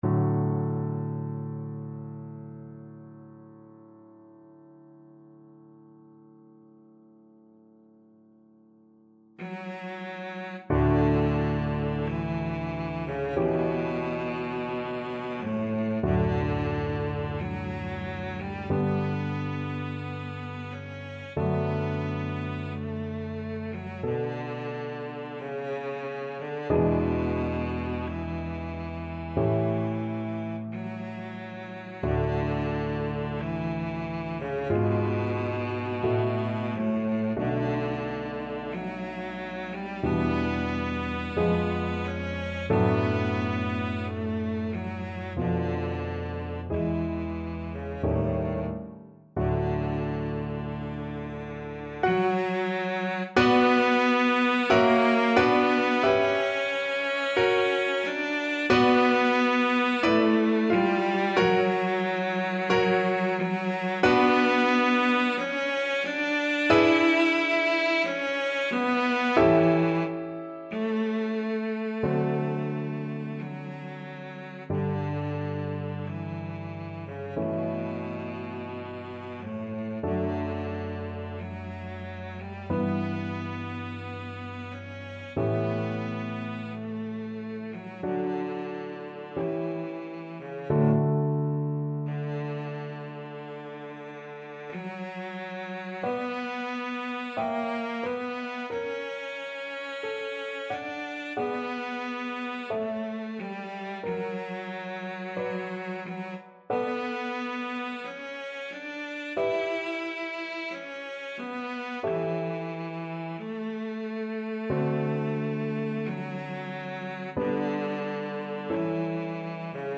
Cello version
4/4 (View more 4/4 Music)
Adagio =45
Classical (View more Classical Cello Music)